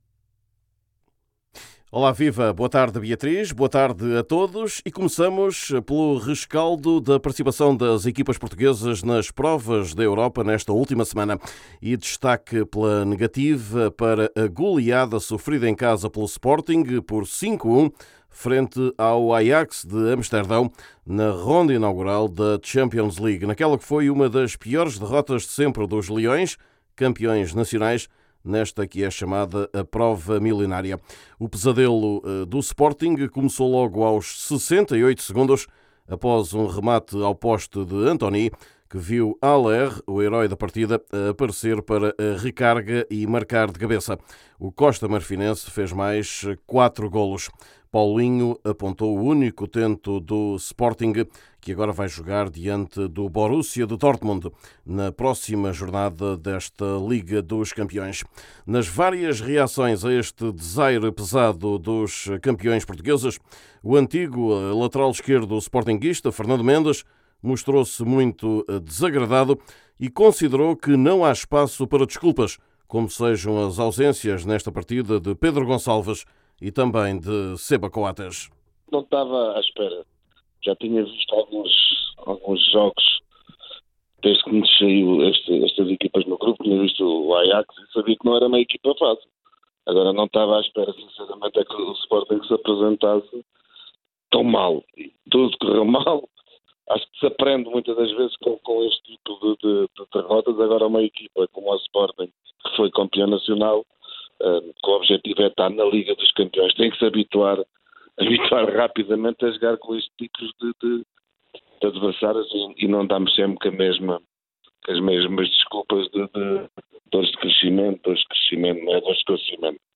Destaque para o desaire pesado do Sporting em casa, contra o Ajax, na estreia da fase de grupos da Champions League desta época. Vamos escutar a reacção de um antigo jogador dos leões de Lisboa.